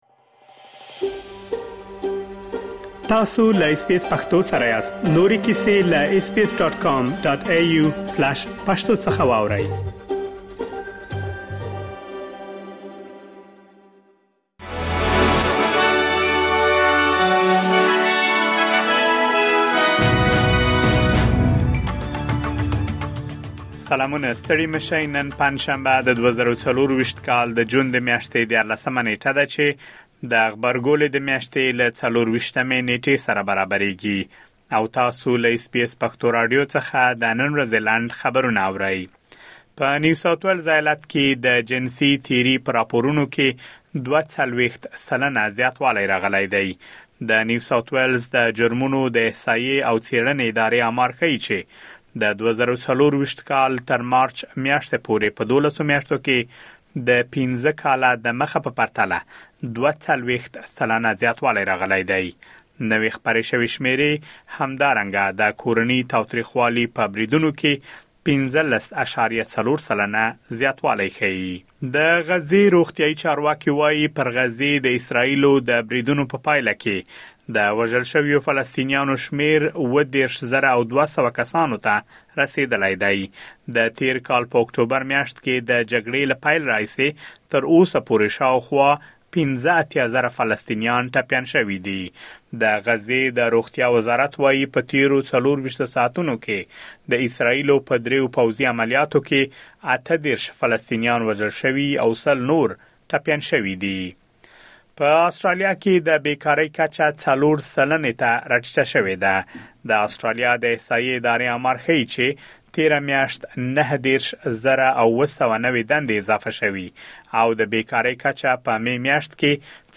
د اس بي اس پښتو د نن ورځې لنډ خبرونه|۱۳ جون ۲۰۲۴